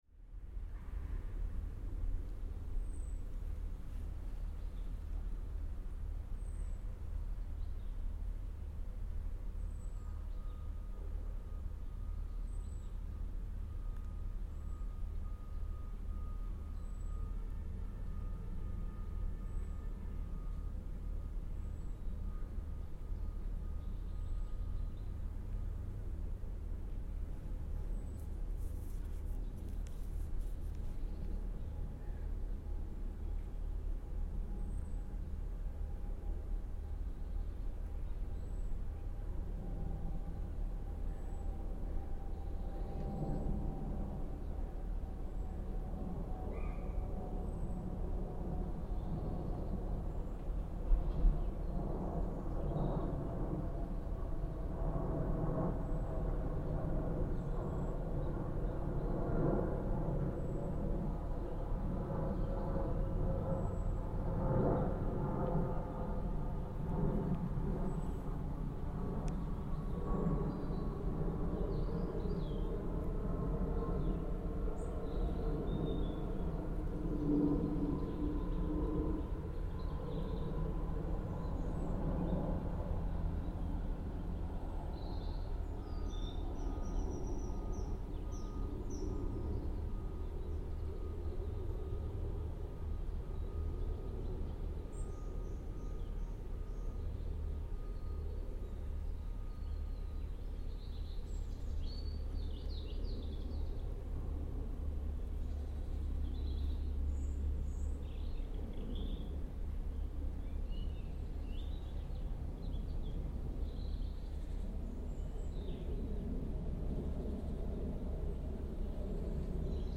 Low rumbling soundscape recorded at Middlesex filter beds Nature Reserve: Vehicles and activity from the adjacent industrial estate merge with aircraft, wildlife in the nature reserve and a pram wheeled across cobblestones.